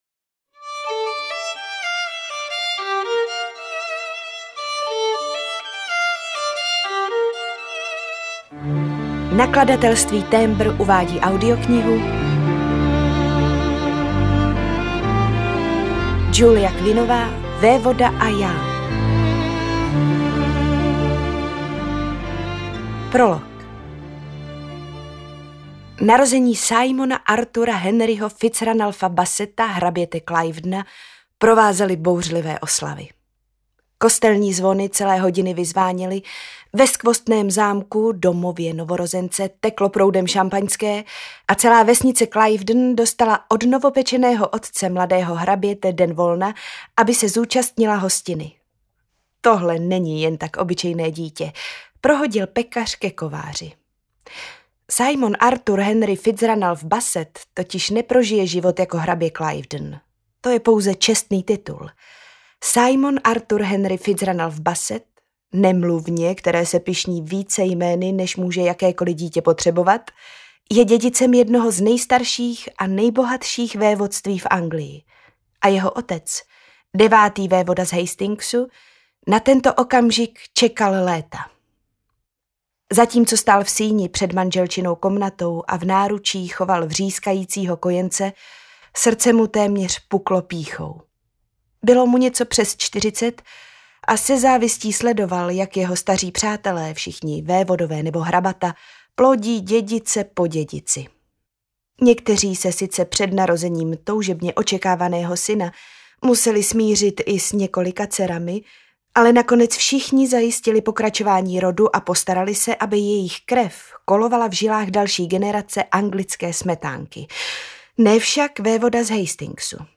Vévoda a já audiokniha
Ukázka z knihy
vevoda-a-ja-audiokniha